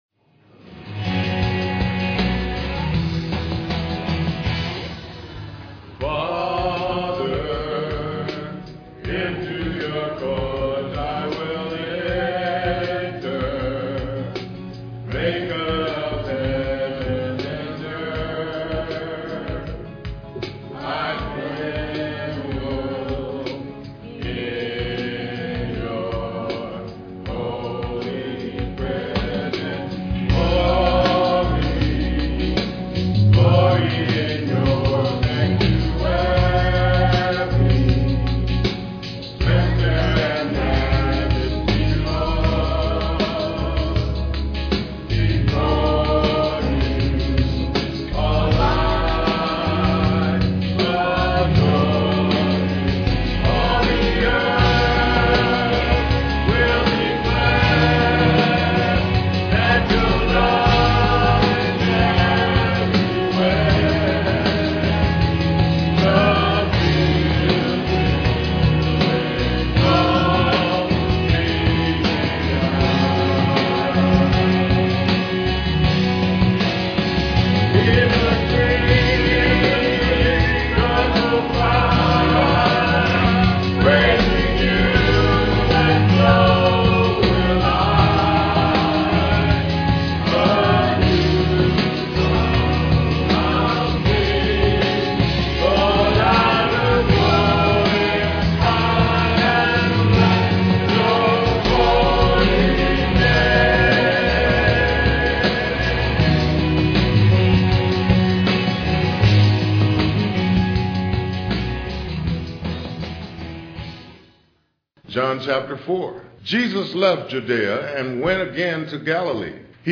Piano offertory